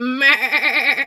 pgs/Assets/Audio/Animal_Impersonations/sheep_2_baa_01.wav at master
sheep_2_baa_01.wav